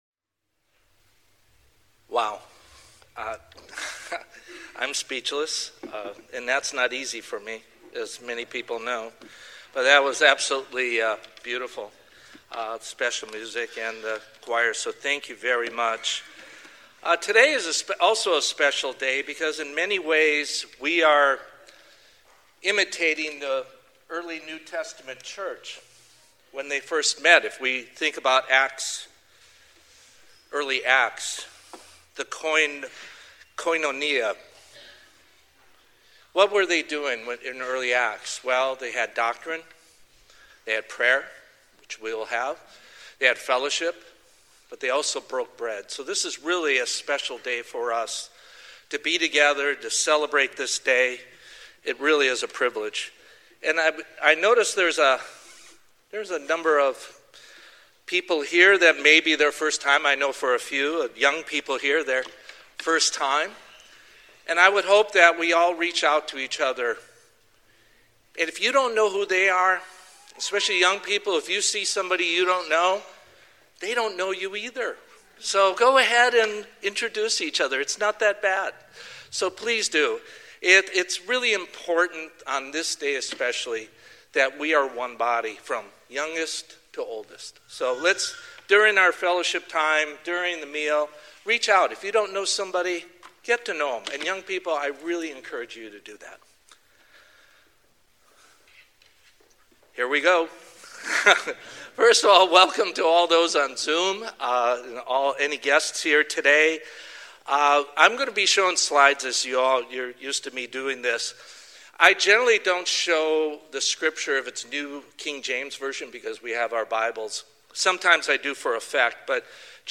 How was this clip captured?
Given in Bakersfield, CA Los Angeles, CA